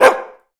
adult_dog_0030.wav